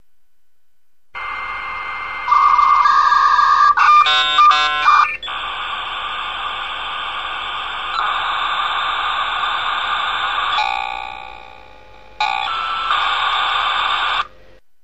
Категория : Звуковые эффекты